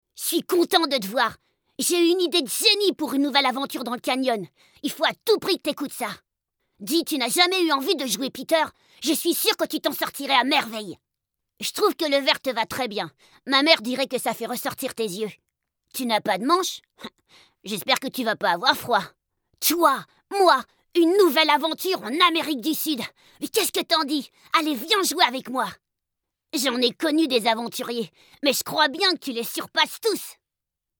Jeune garçon frondeur aventurier
JeuxVideoGarconFrondeurAventurier12ans.mp3